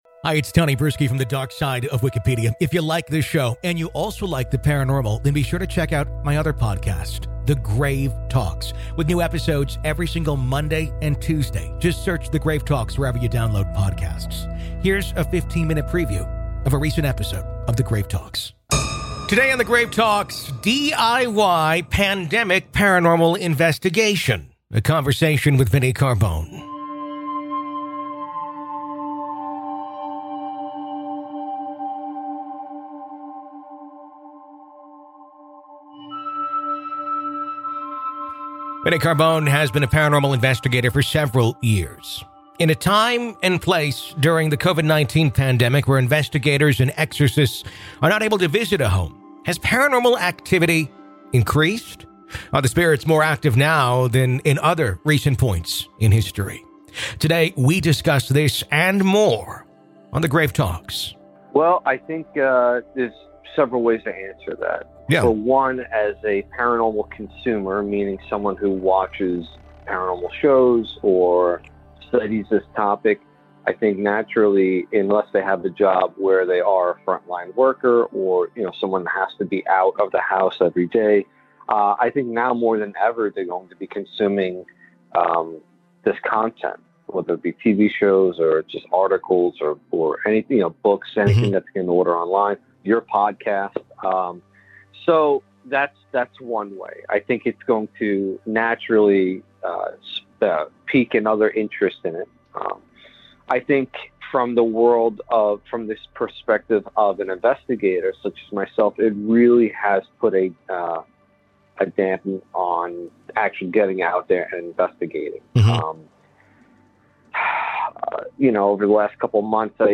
PART 2 - AVAILABLE TO GRAVE KEEPERS ONLY - LISTEN HERE In part two of our interview, available only to Grave Keepers , we discuss: What are some mistakes to avoid in using these?